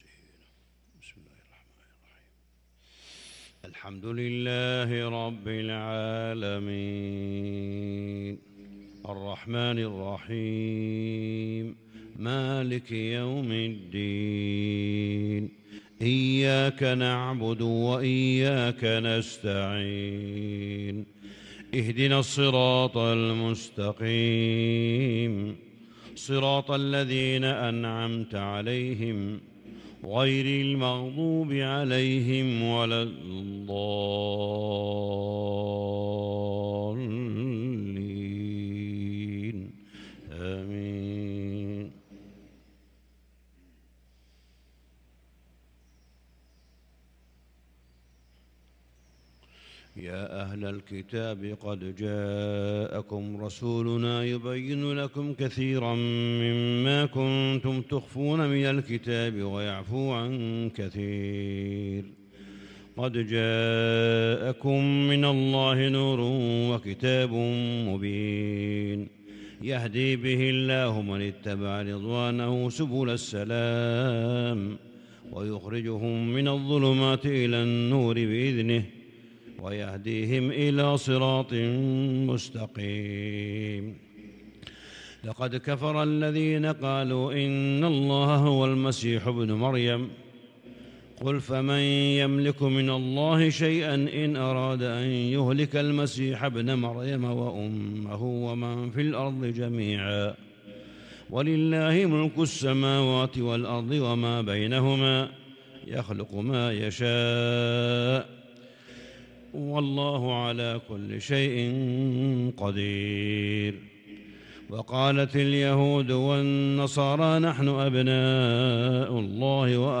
صلاة الفجر للقارئ صالح بن حميد 28 صفر 1444 هـ
تِلَاوَات الْحَرَمَيْن .